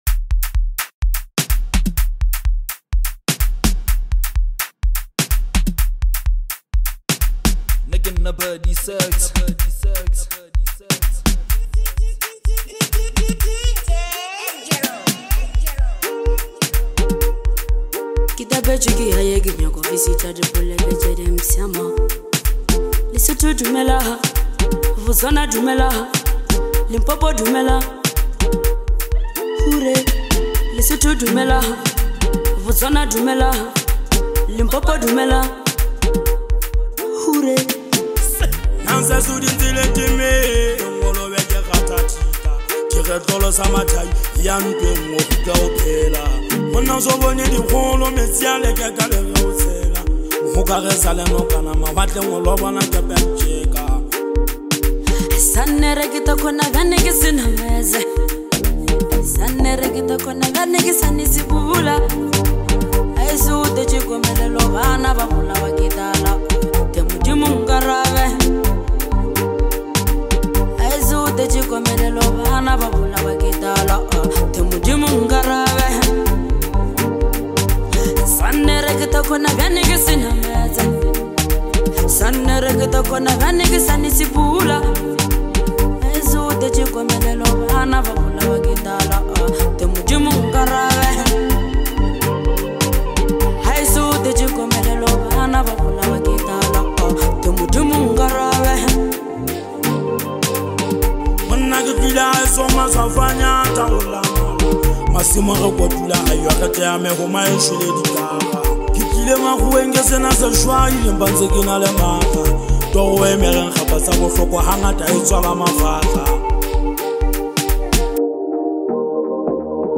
Talented South African singer and songwriter